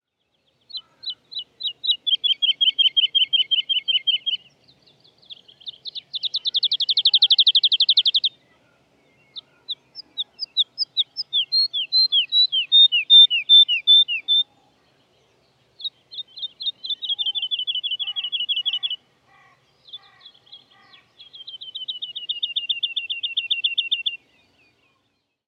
Het geluid van een Boomleeuwerik
• De boomleeuwerik staat bekend om zijn melancholische en melodieuze zang die uniek is in de natuur.
• Je kunt de zang van de boomleeuwerik herkennen aan patronen zoals “lu-lu-lu” en “tie-de-dlie”.
De zang van de boomleeuwerik klinkt als een prachtig concert in de natuur, vol met melancholische en melodieuze tonen die de lucht vullen.
Deze vogel laat een prachtig repertoire aan geluiden horen dat varieert van somber tot jodelend.
Melodieuze tonen wisselen zich af met melancholische klanken.